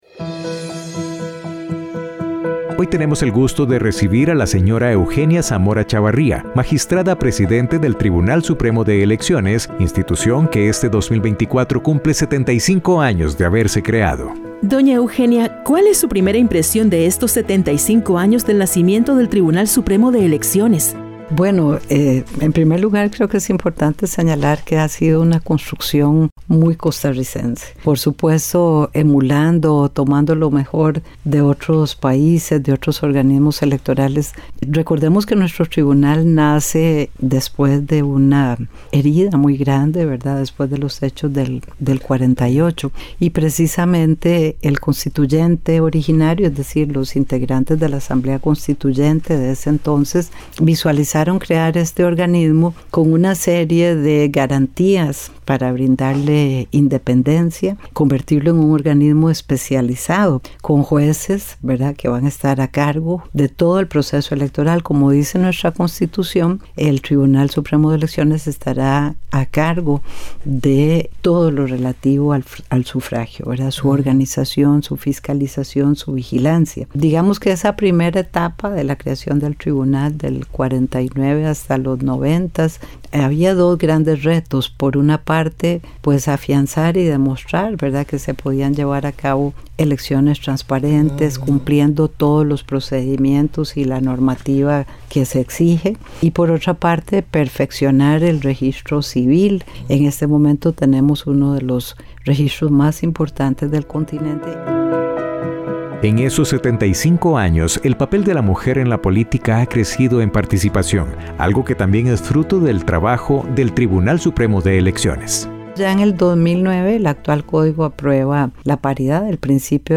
Entrevista a Eugenia Zamora Chavarría, presidente del Tribunal Supremo de Elecciones